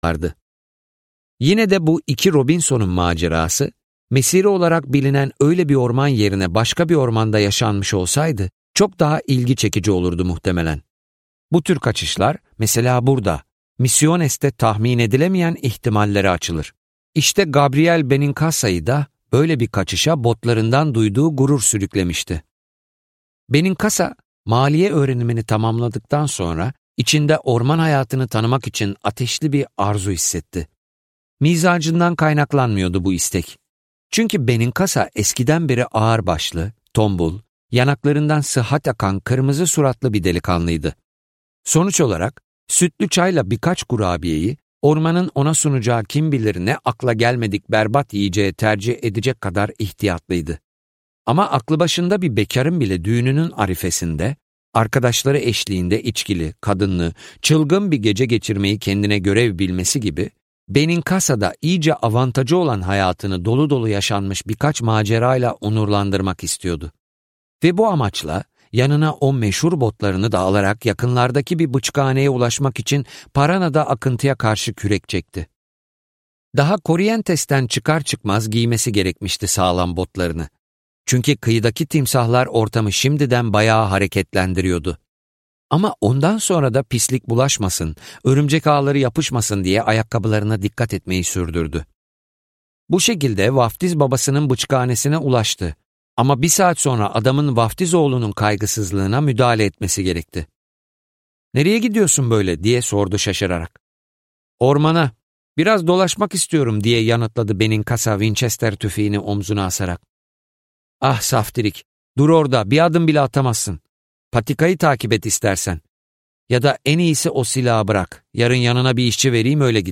Aşk, Delilik ve Ölüm Öyküleri - Seslenen Kitap